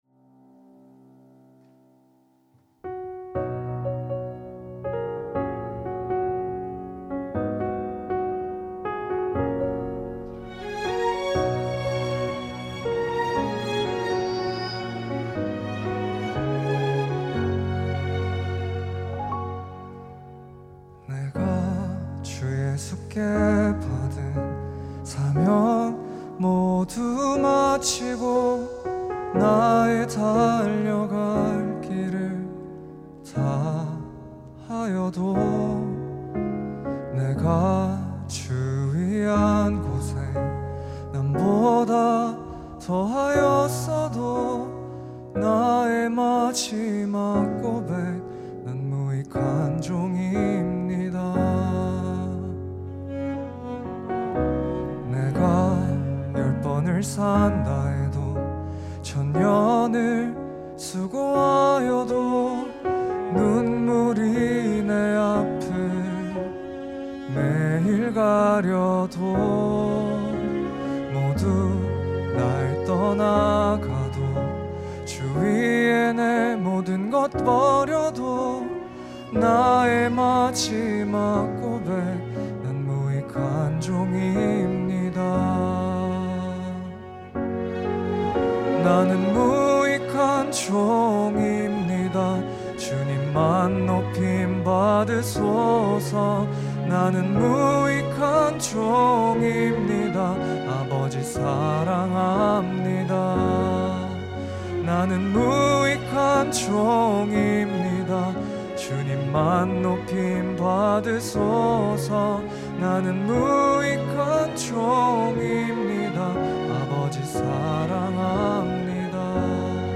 특송과 특주 - 나는 무익한 종입니다